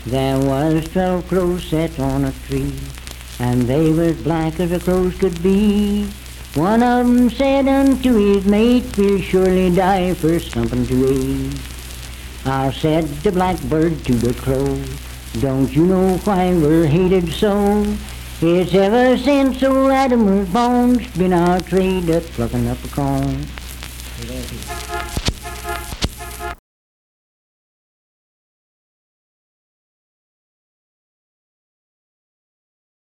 Unaccompanied vocal music
Verse-refrain 2(4).
Performed in Sandyville, Jackson County, WV.
Miscellaneous--Musical
Voice (sung)